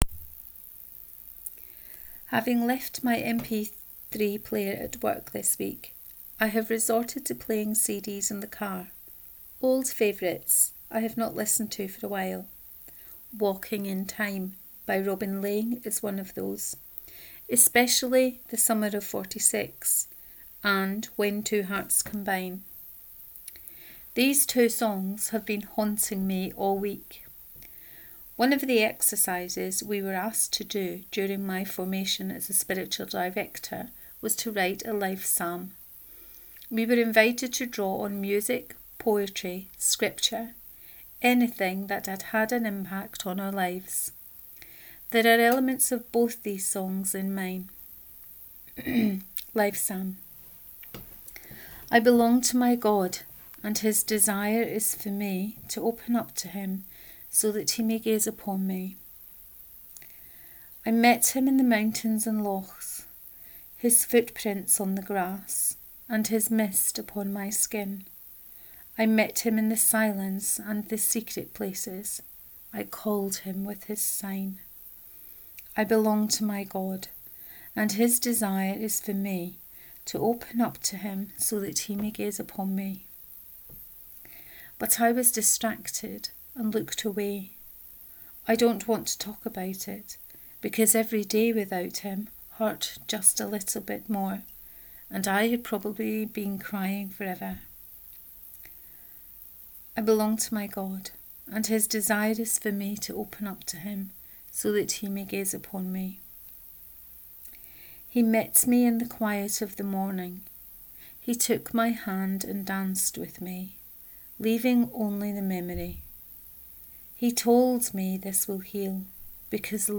The Paradigm Shift 2 : Reading of this post